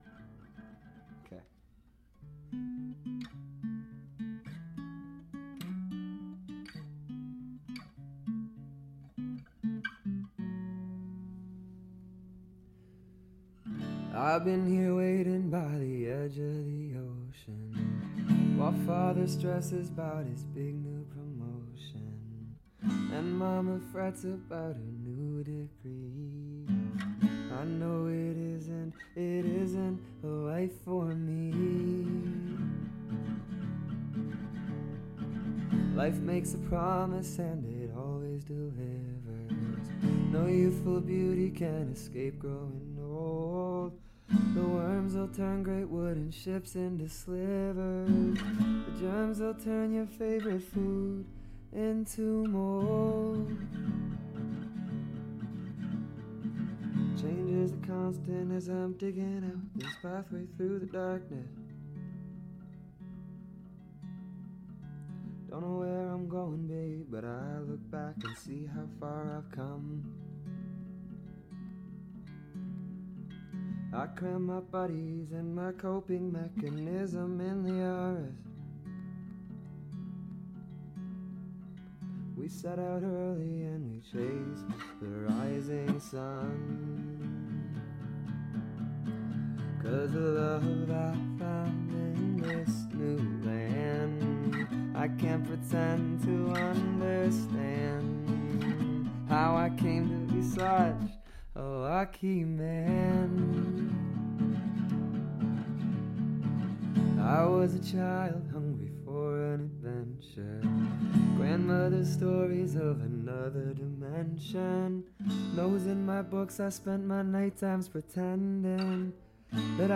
it's not mixed or mastered yet